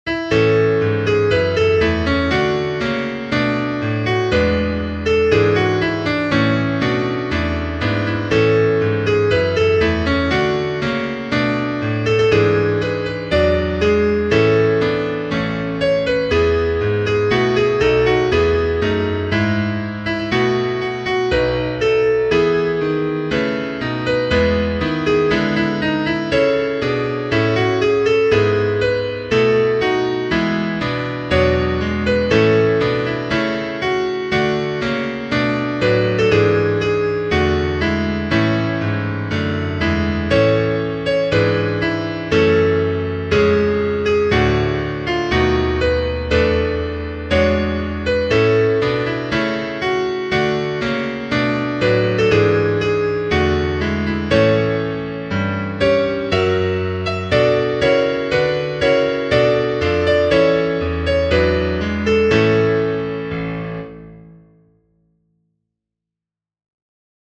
MuseScore rendered piano accompaniment audio in MP3.